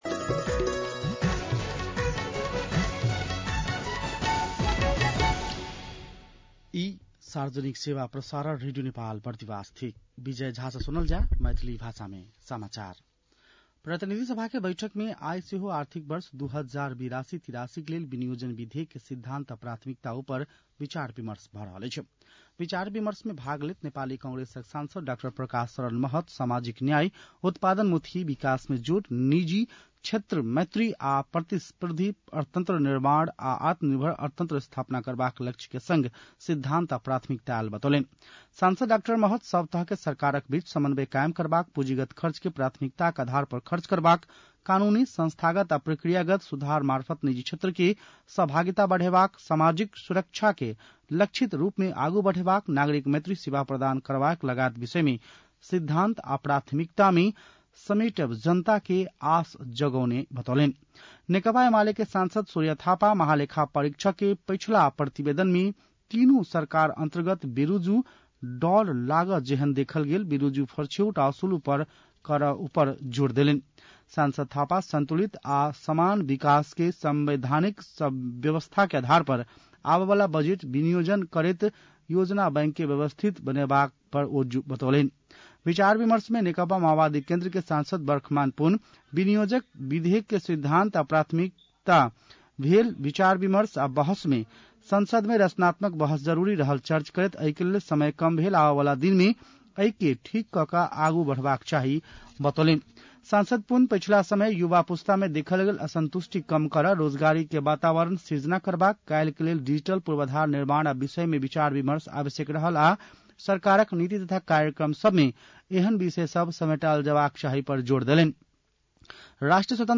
मैथिली भाषामा समाचार : १ जेठ , २०८२
6-pm-maithali-news.mp3